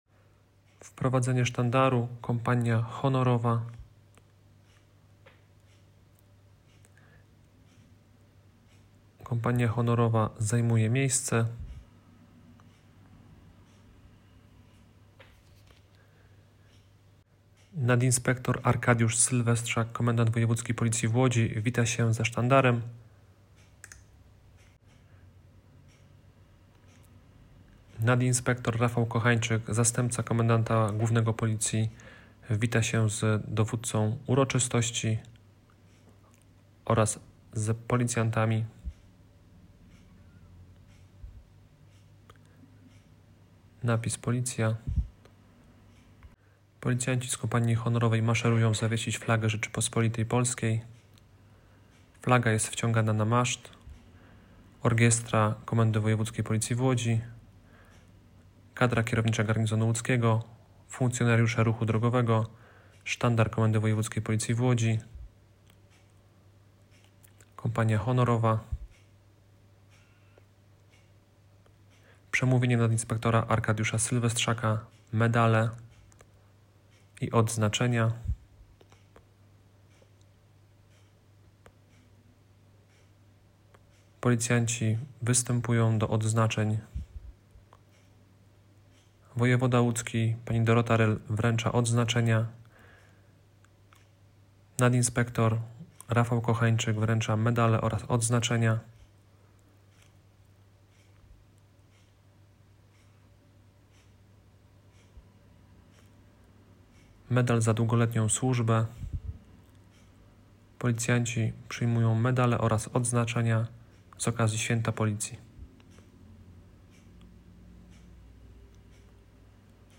Uroczysta defilada, awanse, odznaczenia i słowa wdzięczności – tak 29 lipca 2025 roku na Pasażu Schillera w Łodzi świętowano policyjne święto. Wspólne obchody zorganizowane przez Komendę Wojewódzką i Miejską Policji, były nie tylko wyrazem uznania dla trudnej i odpowiedzialnej służby, ale także symbolicznym przypomnieniem, że mundur to zobowiązanie – do odwagi, profesjonalizmu i służby drugiemu człowiekowi.